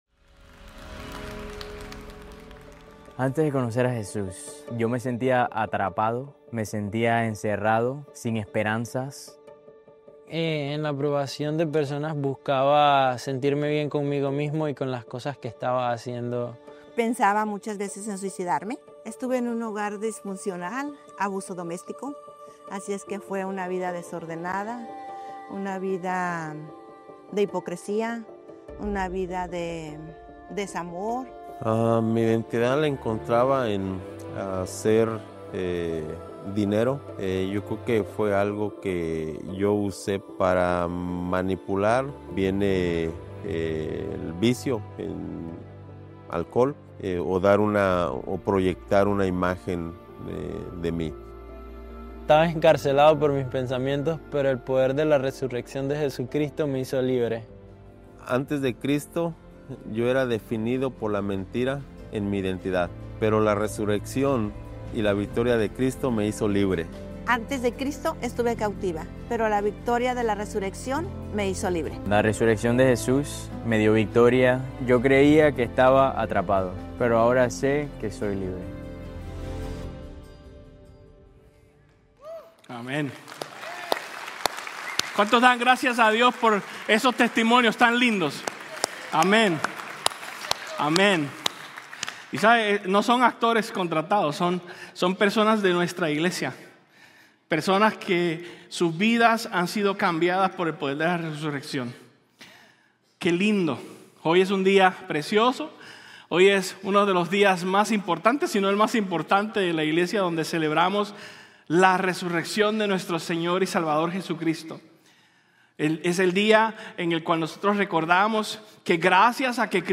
Domingo de Pascua en Iglesia Fielder
Ya sea que te hayas perdido el servicio o quieras volver a escuchar el mensaje, oramos que fortalezca tu fe y te acerque más a Jesús.